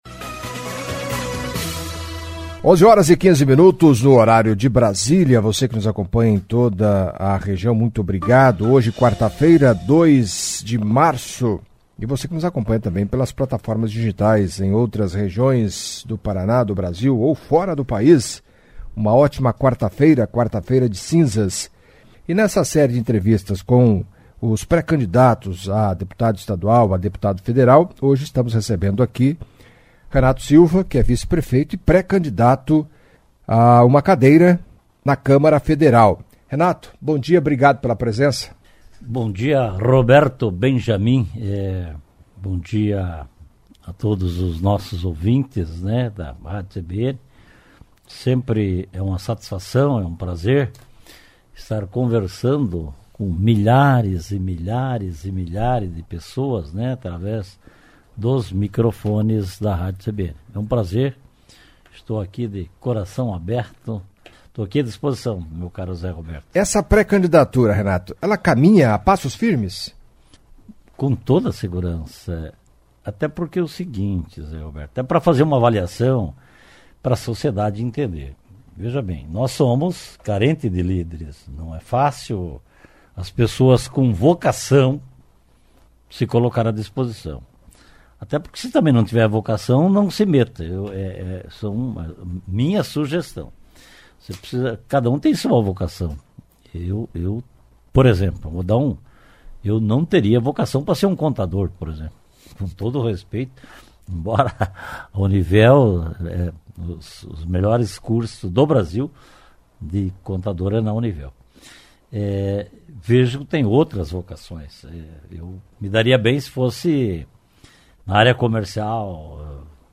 Na série de entrevistas com os pré-candidatos a deputado federal com domílicio eleitoral em Cascavel, a CBN ouviu nesta quarta-feira (02) o empresário e vice-prefeito Renato SIlva. Renato, que é do Republicanos, foi taxativo em reafirmar que a sua intenção de ser candidato segue firme e ganha força a cada dia que passa.